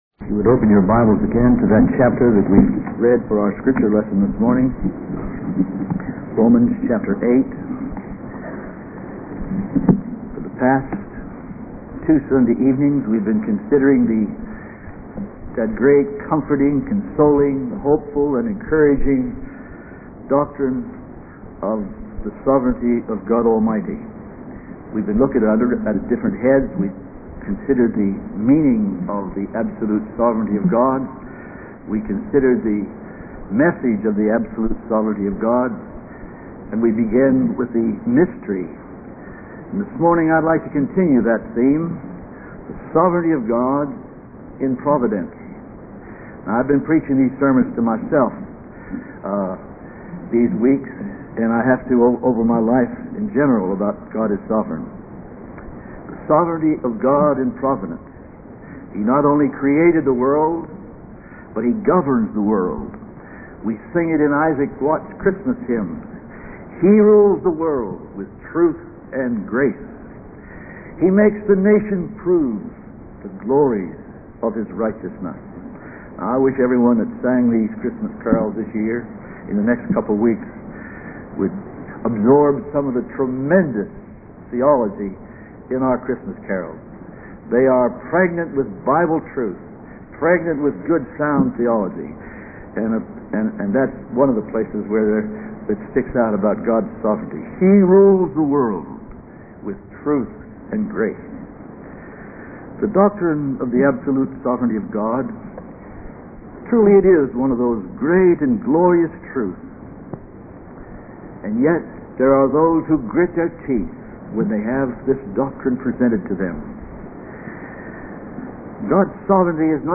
In this sermon, the speaker emphasizes the importance of having a deep love for God and His word. He compares the thirst for God's truth to the thirst for cool water in a dry land. The speaker also highlights the good news of forgiveness and salvation through Jesus Christ.